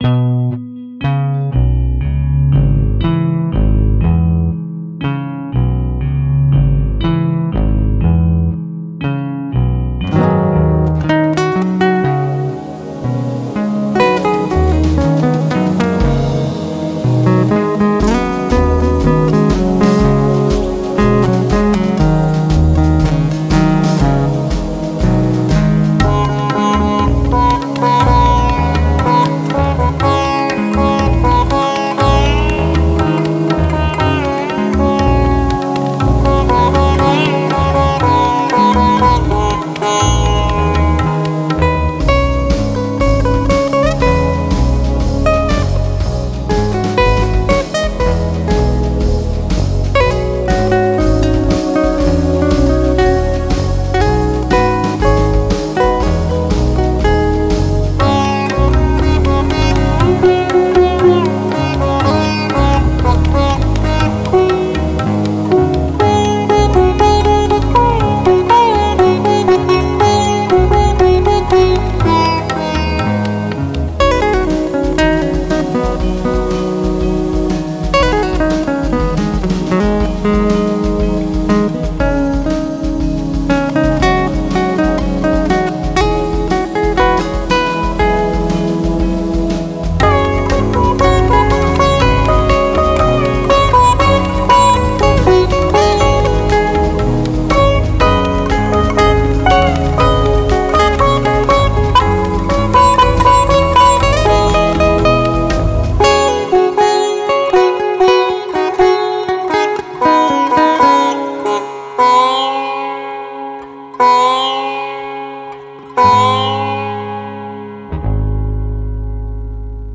Alle Stücke auf dieser Seite liegen in CD-Qualität vor.